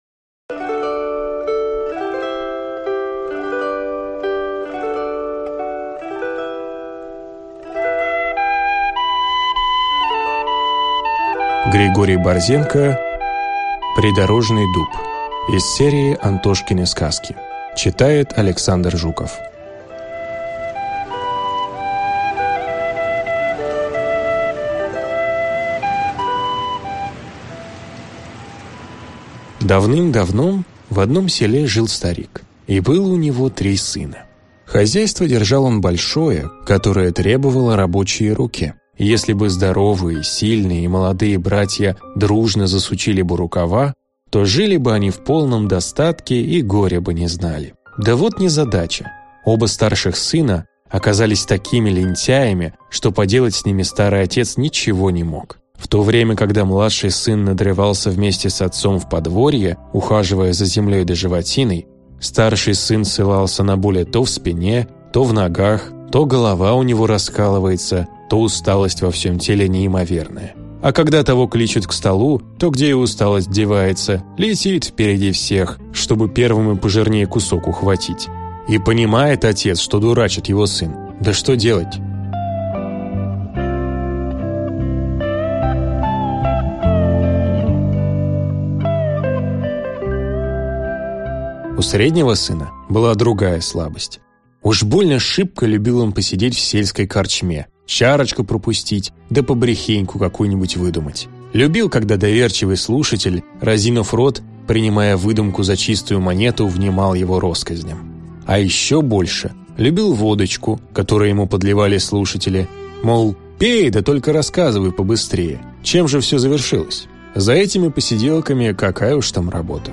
Аудиокнига Придорожный дуб | Библиотека аудиокниг